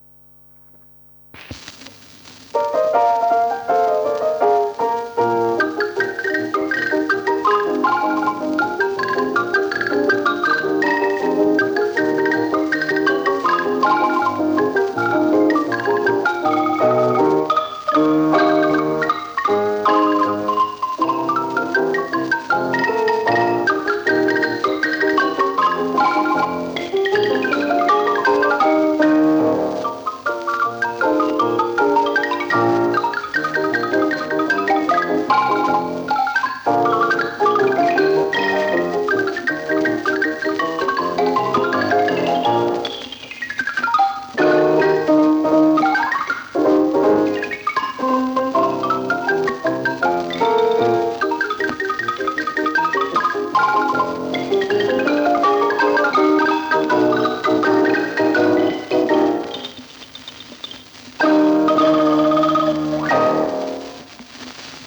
дуэт ксилофонов